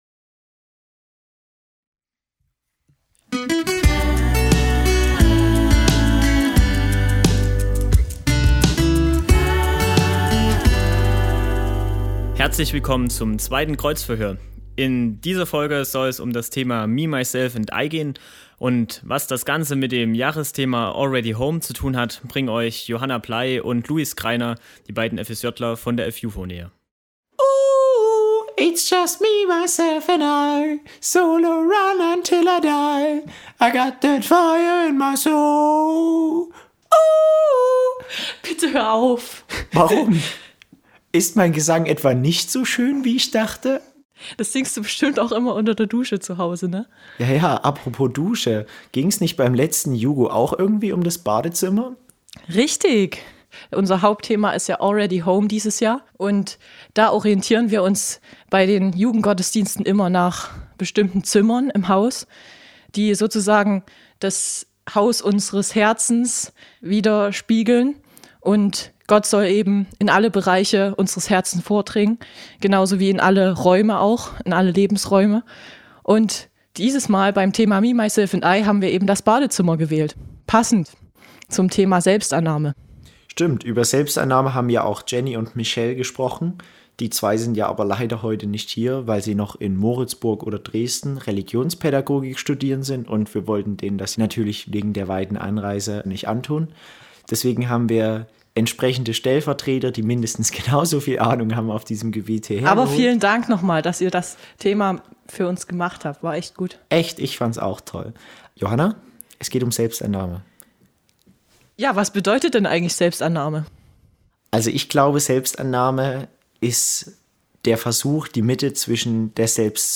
Beschreibung vor 7 Jahren Wie der Name schon vermuten lässt, werden beim Kreuzverhör Prediger zu ihren Themen in die Mangel genommen. In einer Kurzweiligen Diskussionsrunde, werden offen gebliebene Fragen geklärt.